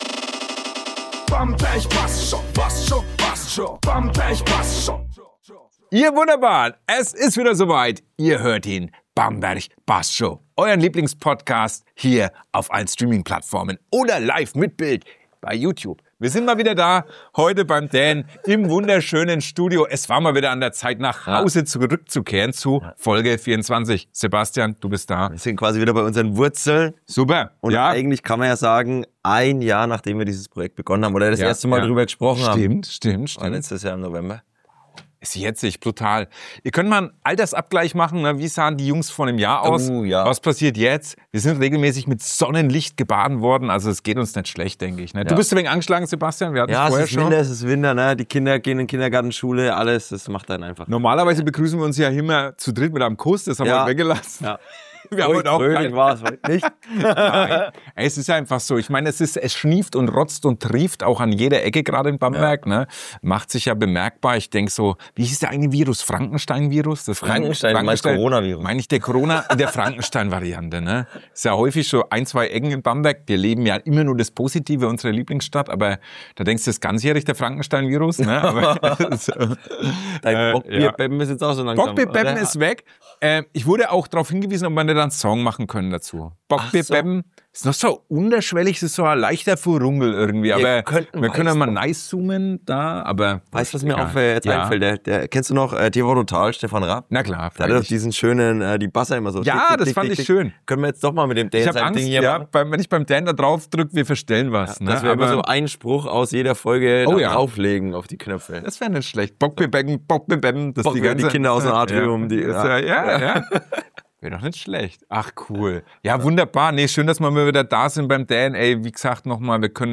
Dazu gibt’s viel Lokalkolorit: Försterklause-Nachklang, Bamberger Kneipen-Nostalgie, erste Pläne für den Winter, verrückte Weihnachtsmarkterlebnisse und einen Blick darauf, warum Bamberg zur dunklen Jahreszeit besonders leuchtet. Warm, witzig, weihnachtlich – eine typische „Bassd scho“-Folge mit Herz.